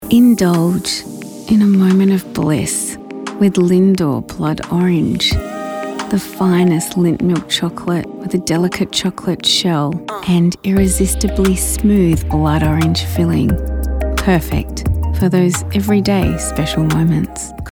Female
English (Australian)
Adult (30-50)
A friendly, warm and empathic voice with a bubbly and catchy energy.
Radio Commercials
Sultry, Romance, Lindor Ad